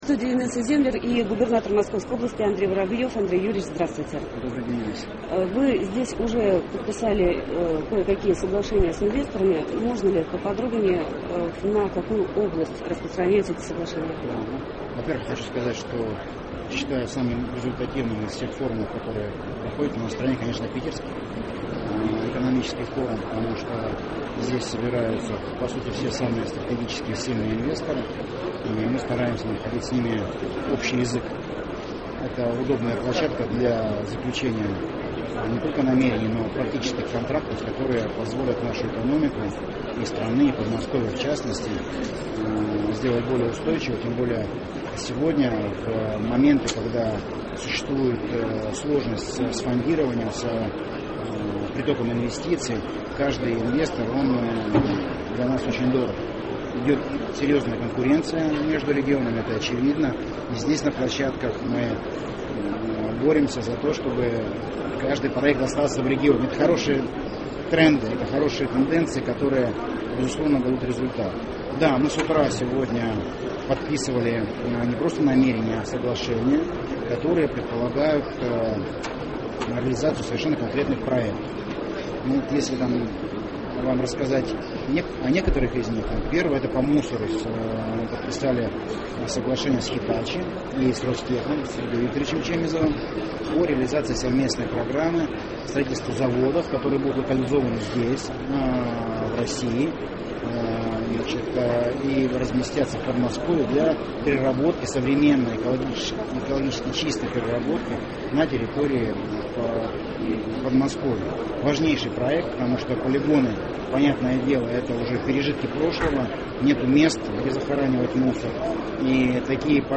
Интервью
Андрей Воробьев - Интервью - 2015-06-19